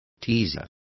Complete with pronunciation of the translation of teasers.